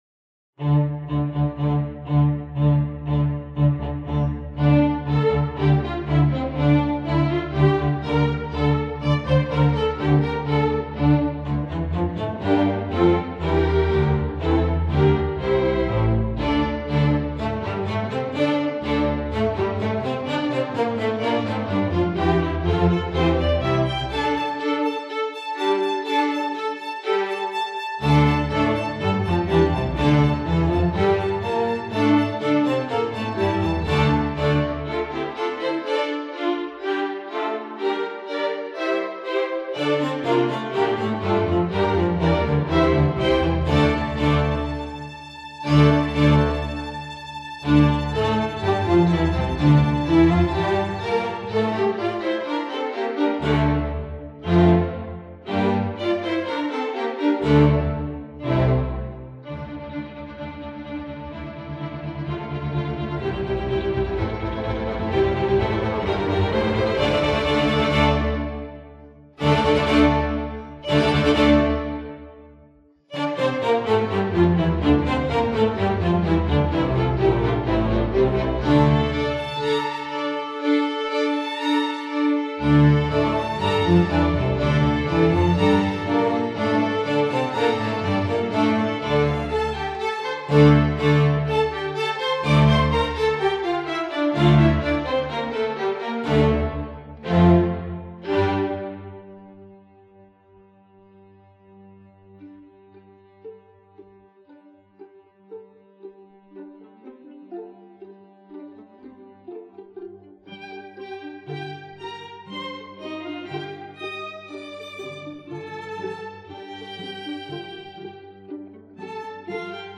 Instrumentation: string orchestra